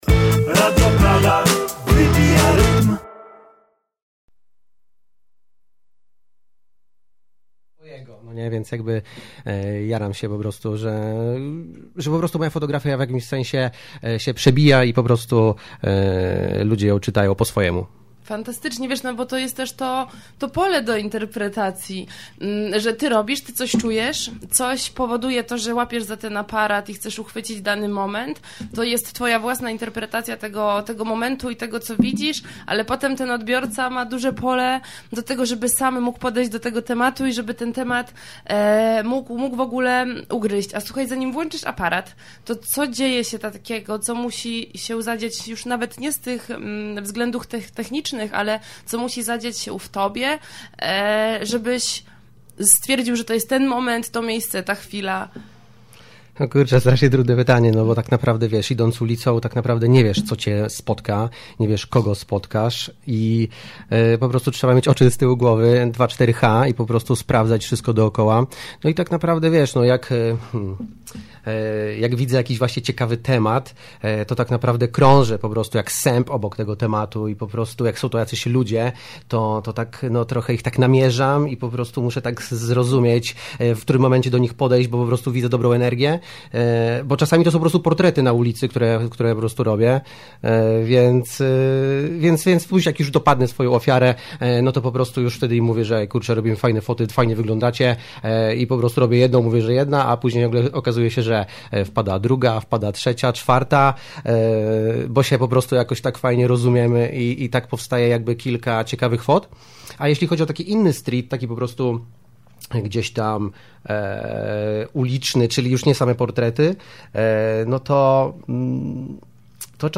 Początek audycji przepadł. Technicznie – po prostu nie został nagrany. Przepadły życzenia z okazji Dnia Ojca i wprowadzenie do rozmowy, ale wszystko, co najważniejsze, brzmi już od pierwszych sekund nagrania.